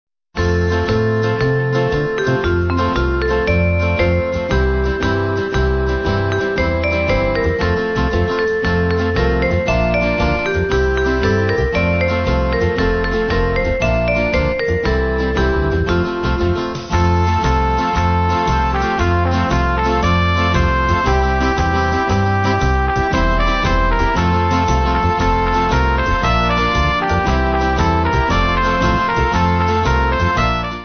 Kid`s club music